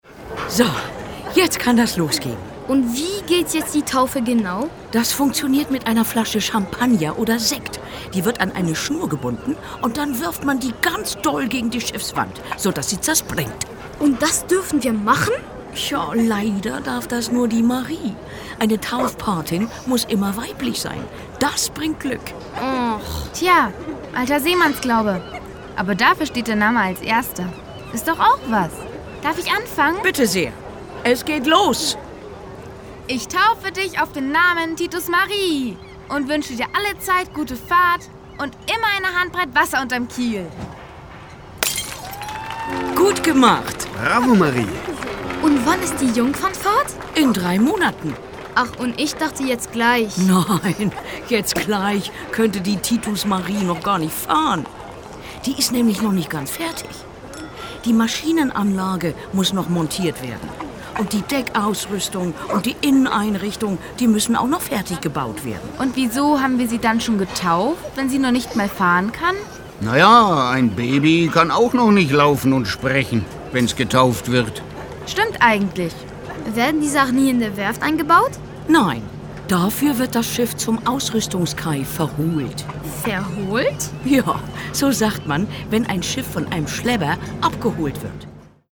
Lustige Reime und Songs!
Hier gibt es jede Menge Wissen, verpackt in unterhaltsame Dialoge und originelle Reime. Tolle Lieder laden außerdem zum Mitsingen ein.